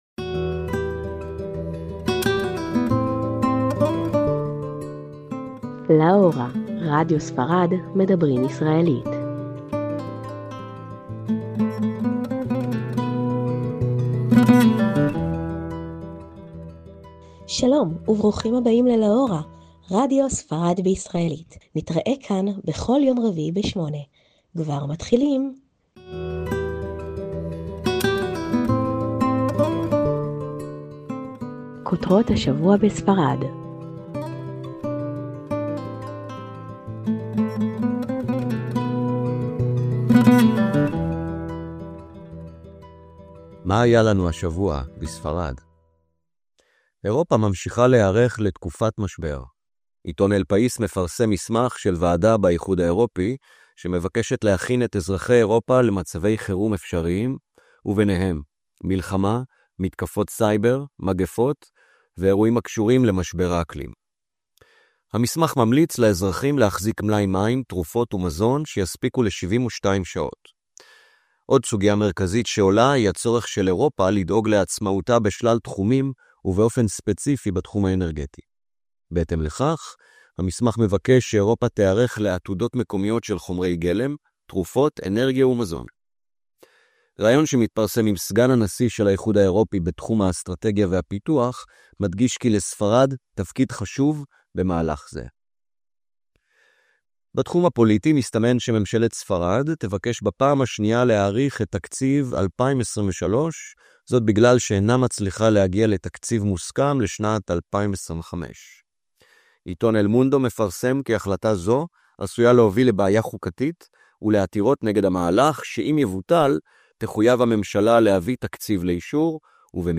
"LA HORA": MEDABRÍM ISRAELÍT - לה הורה״ – תכנית רדיו בעברית לטובת הישראלים בספרד"